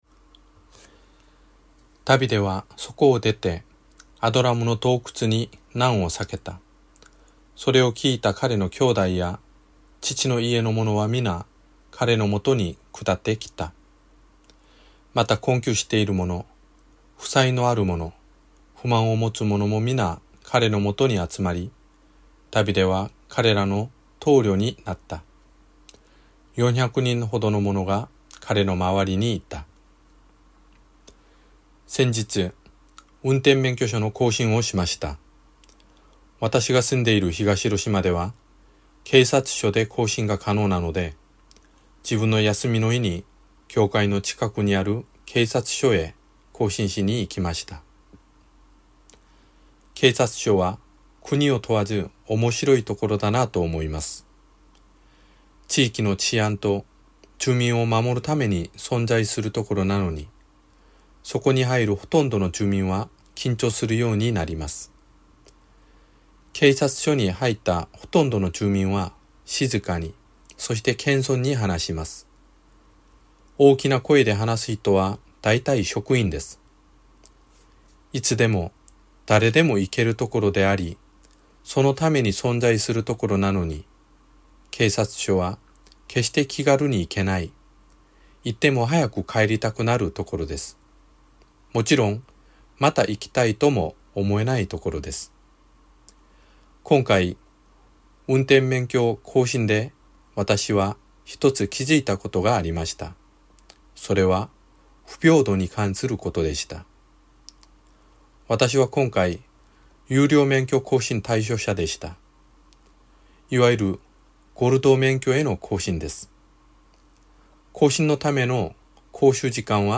ラジオ番組「キリストへの時間」